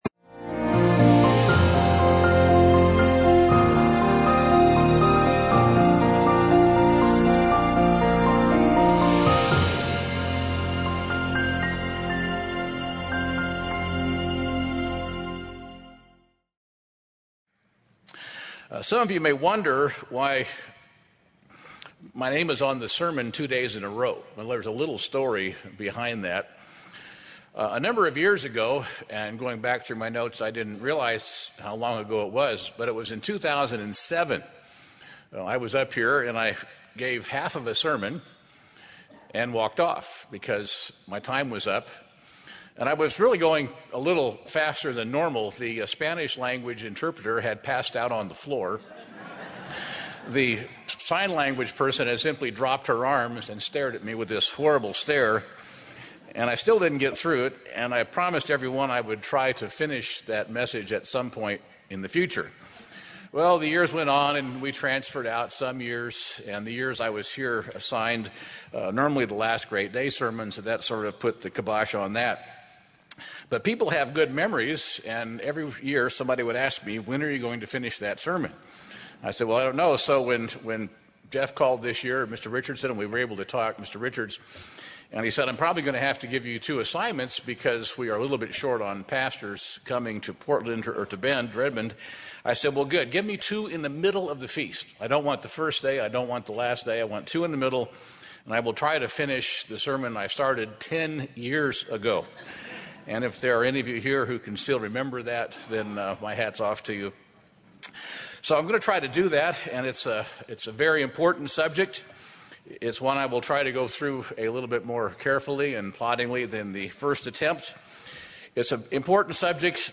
This sermon was given at the Bend-Redmond, Oregon 2017 Feast site.